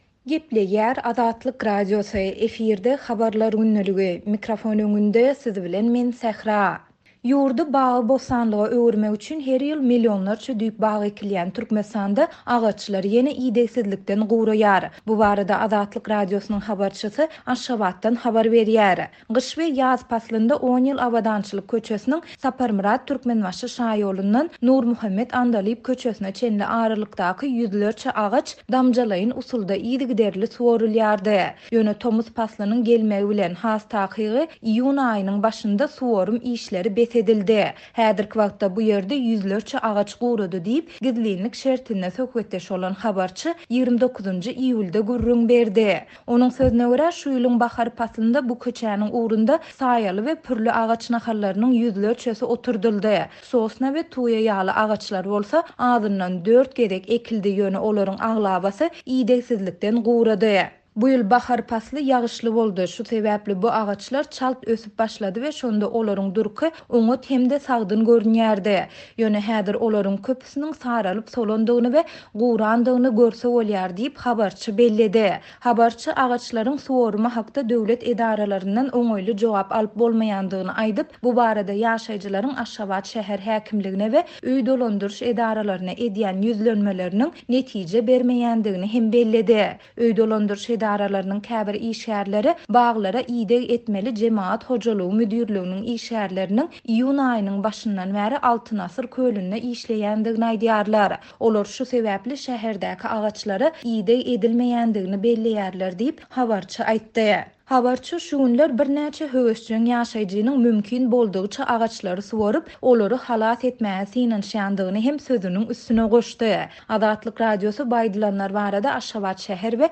Ýurdy bagy-bossanlyga öwürmek üçin her ýyl millionlarça düýp bag ekilýän Türkmenistanda agaçlar ýene idegsizlikden guraýar. Bu barada Azatlyk Radiosynyň habarçysy Aşgabatdan habar berýär.